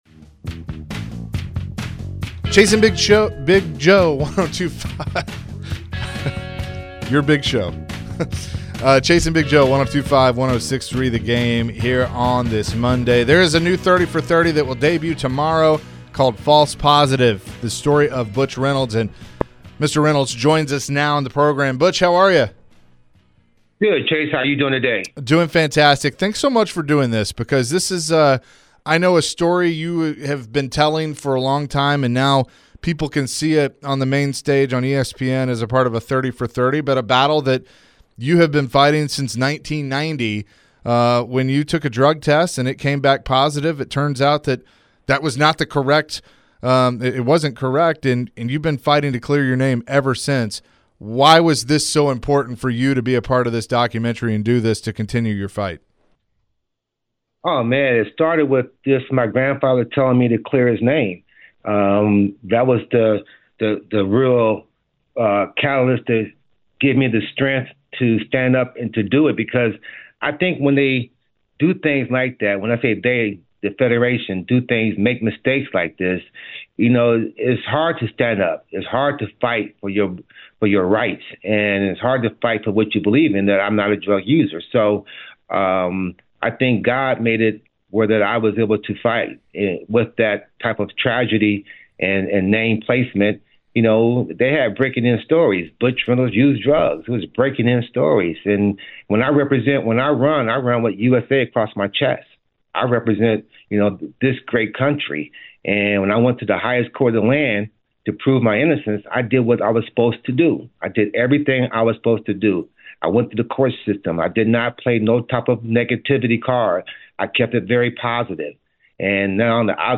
Butch Reynolds joined the show and shared his story about the new 30 for 30 coming out called “False Positive”. Butch shared his story and why he’s innocent.